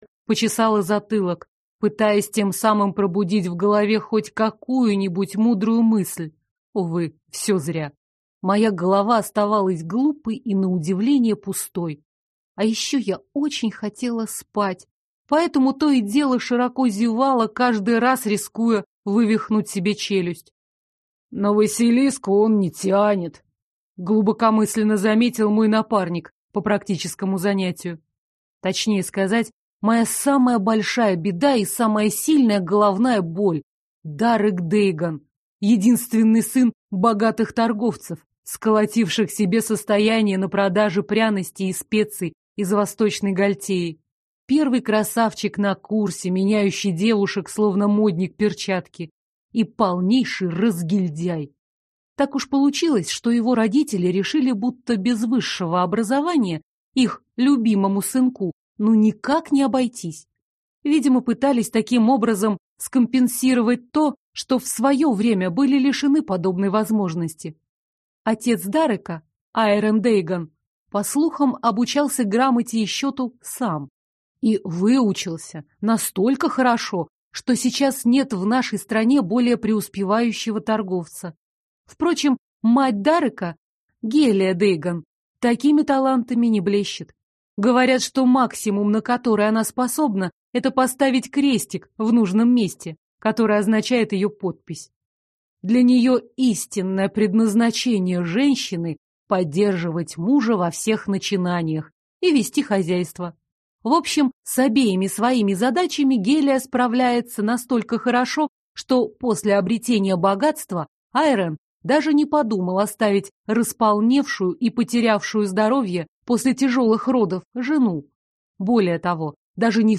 Аудиокнига Пособие для ленивого студента - купить, скачать и слушать онлайн | КнигоПоиск